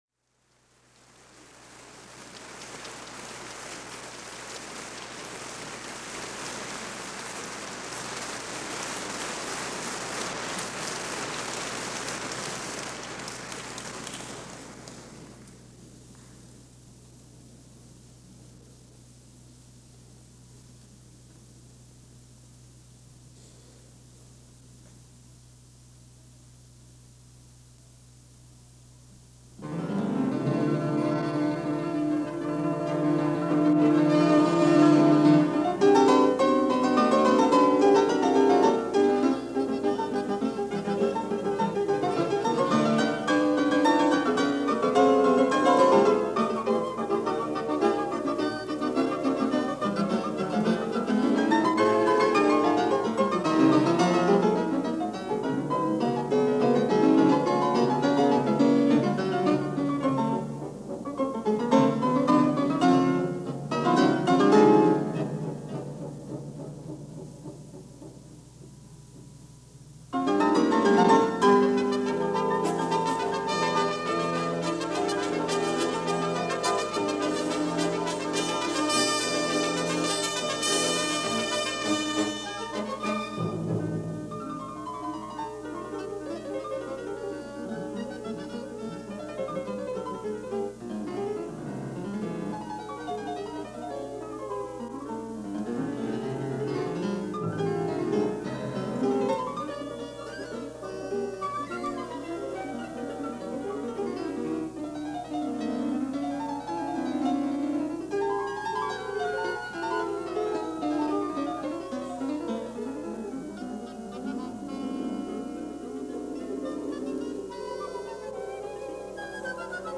Another rare concert this week. From the 1959 Vienna Festival in Austria, a concert by The Vienna Symphony conducted by the legendary Ernest Bour and featuring two other legends, Pianist Geza Anda and violinist Yehudi Menuhin in an all Bartok concert.